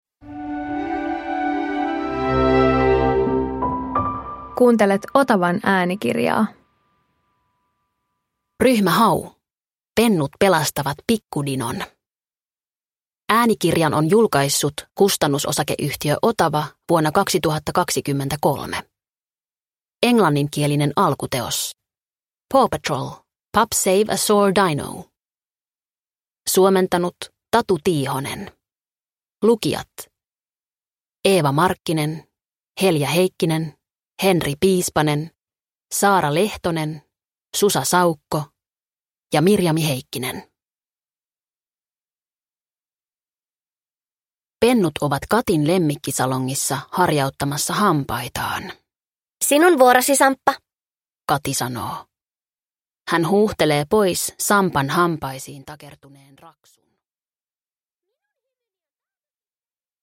Ryhmä Hau - Pennut pelastavat pikkudinon – Ljudbok – Laddas ner